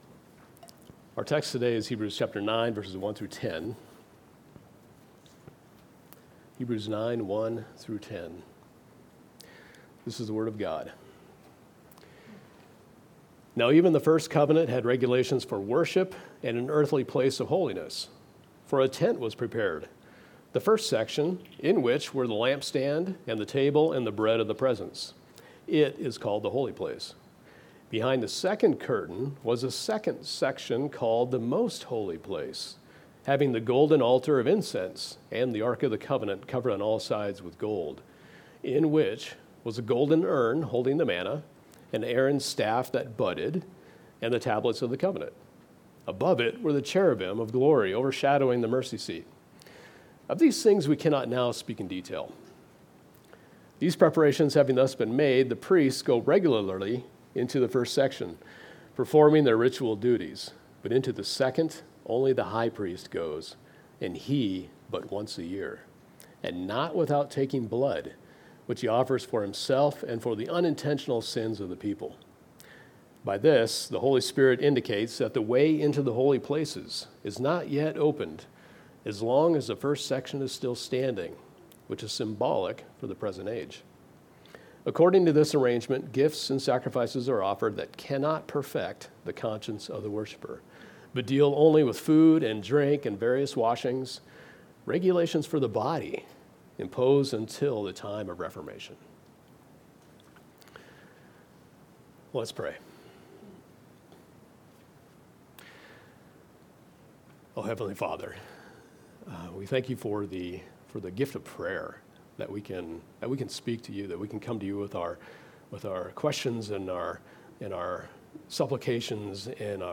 Expositional sermons and Sunday school lessons from Sojourn Church in Spearfish, SD.